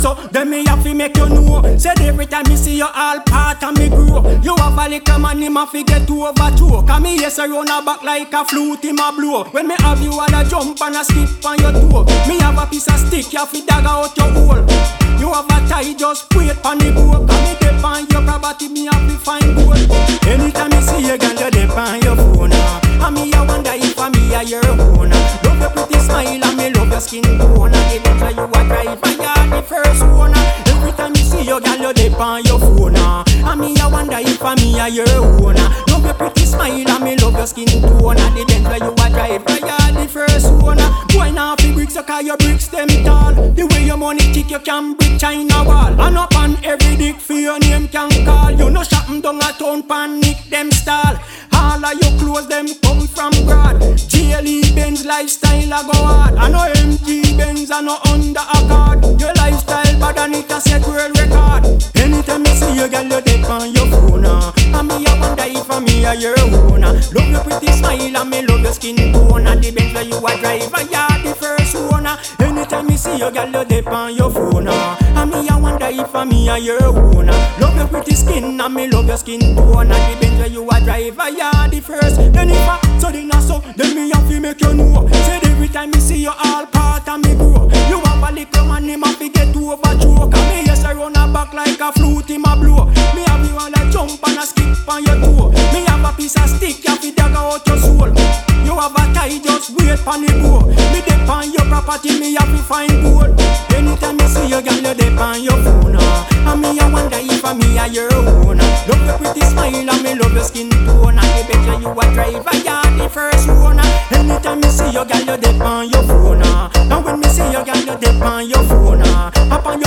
New Release Bass / Dubstep Dancehall